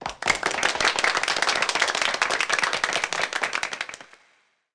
Applause Sound Effect
Download a high-quality applause sound effect.
applause-2.mp3